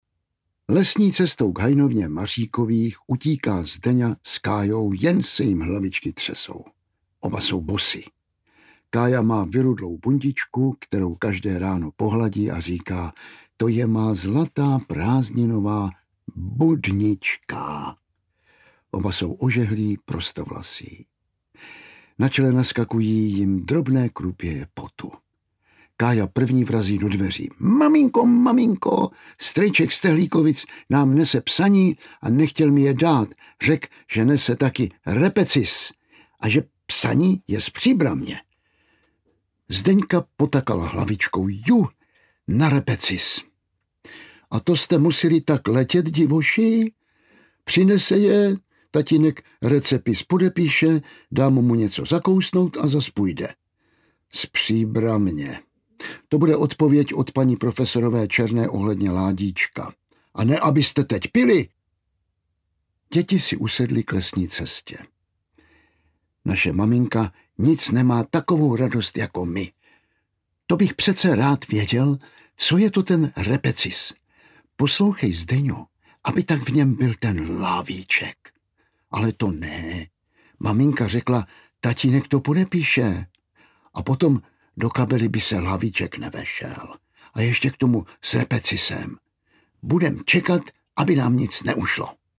Školák Kája Mařík 2 audiokniha
Ukázka z knihy
• InterpretJan Vlasák
skolak-kaja-marik-2-audiokniha